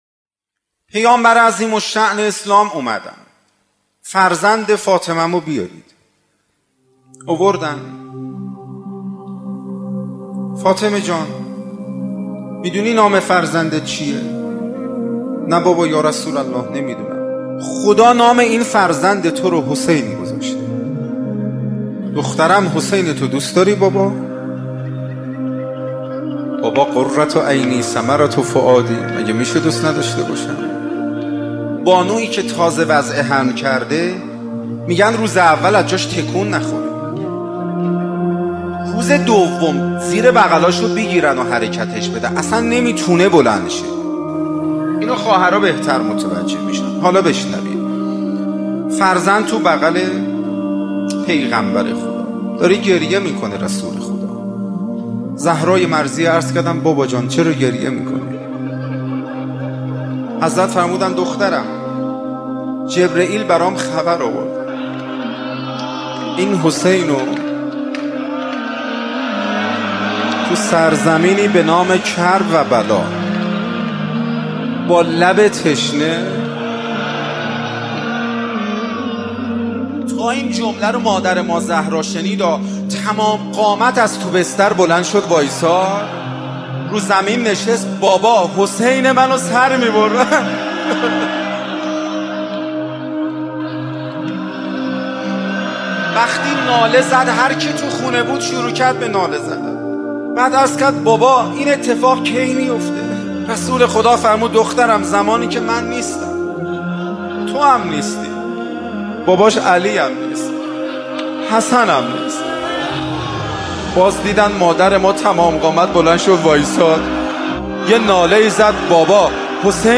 همزمان با ماه محرم، مجموعه پادکست «محرم، فصل خون» با کلام اساتید به نام اخلاق به کوشش ایکنا گردآوری و تهیه شده است، که دهمین قسمت این مجموعه با کلام حجت‌الاسلام هاشمی‌نژاد با عنوان «غربت حسین (ع)» تقدیم مخاطبان گرامی ایکنا می‌شود.